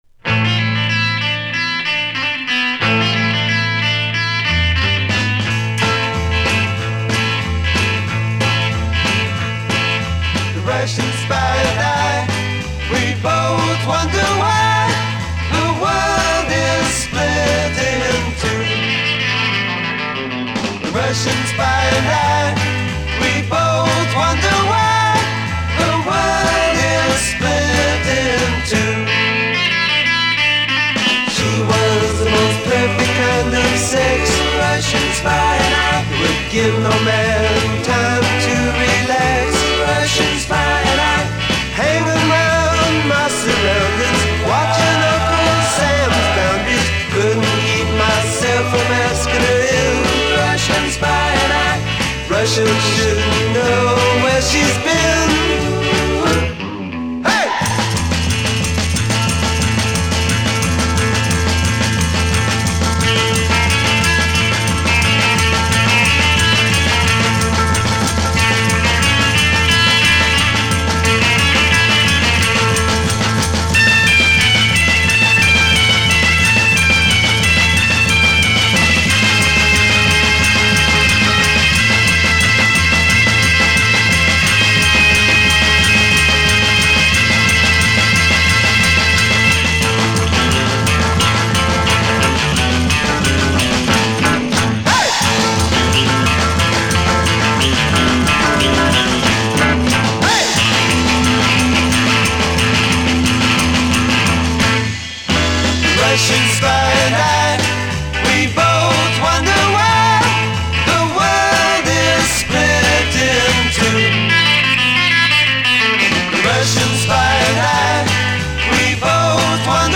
Какой то Чардаш получается.